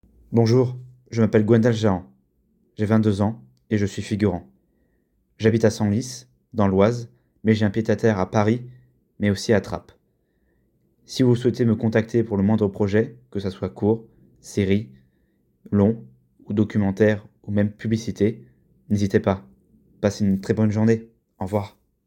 Bande son
19 - 29 ans - Baryton